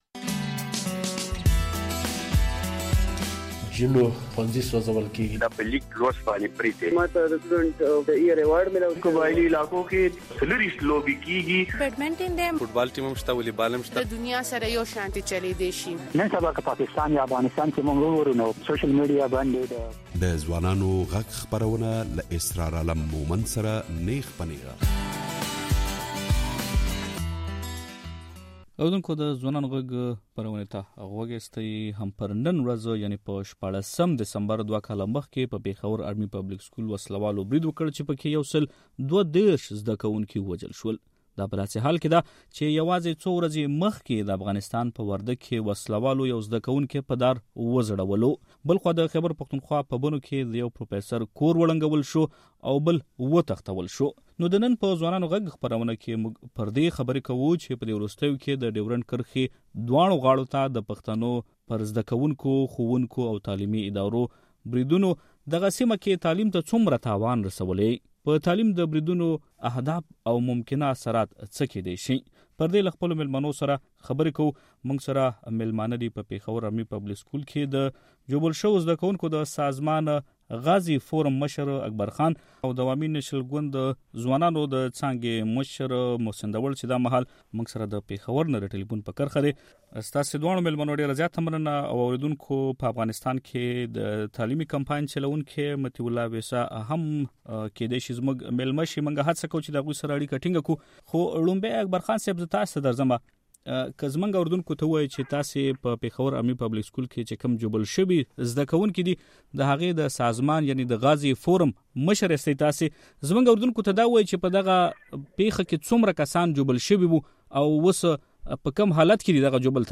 دې دواړو او د مشال راډيو اوريدونکو سره مو پر دې خبرې وکړې چې د ډيورنډ کرښې دواړو غاړو ته پر پښتنو زده کوونکو ، ښوونکو او تعليمی ادارو بريدونو په دغه سيمه کې تعليم ته څومره تاوان رسولی او د پښتنو پر تعليم د دې بريدونو هدف څه دی: